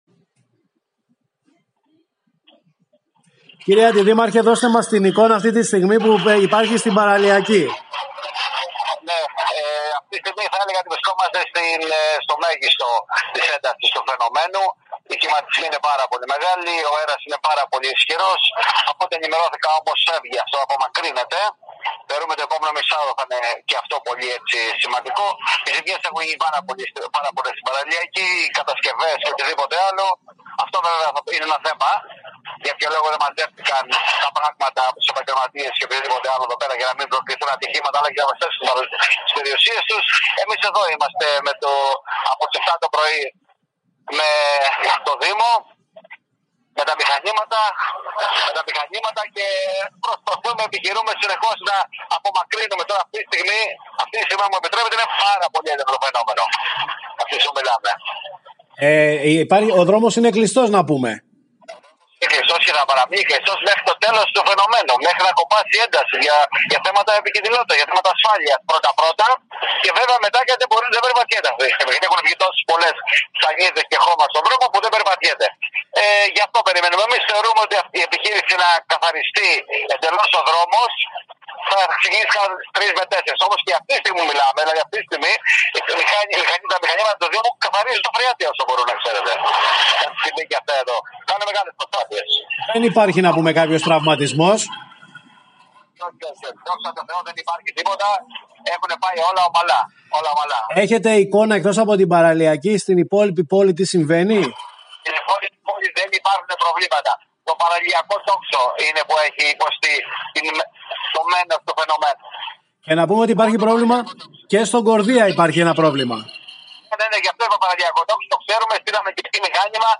ΣΕ ΕΞΑΡΣΗ ΤΟ ΦΑΙΝΟΜΕΝΟ ΑΥΤΗ ΤΗΝ ΩΡΑ ΣΤΗΝ ΚΑΛΑΜΑΤΑ – ΤΙ ΔΗΛΩΣΕ Ο ΑΝΤΙΔΗΜΑΡΧΟΣ Θ.ΒΑΣΙΛΟΠΟΥΛΟΣ